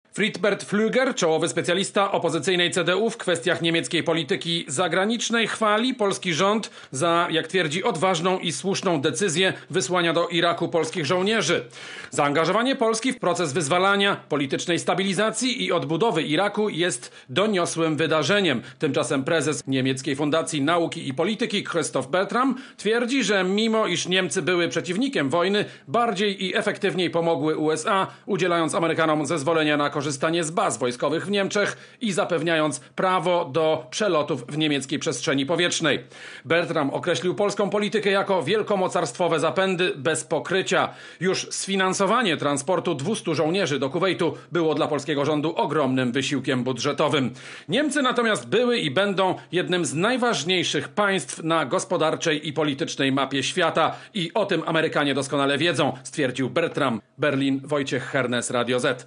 Korespondencja Radia Zet z Berlina (460Kb)